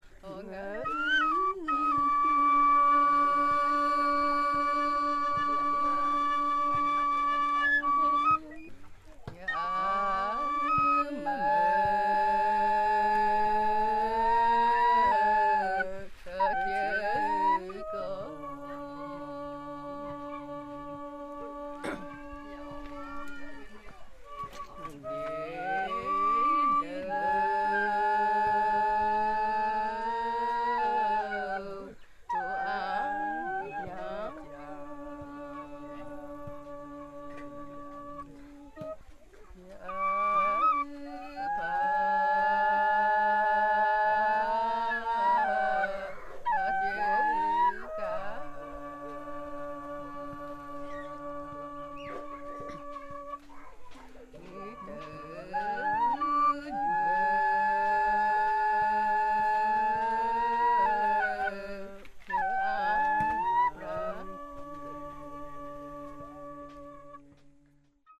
courting song with flute two women sing a rather mournful song led by a duct flute (boys are kicking a football in the background) 1MB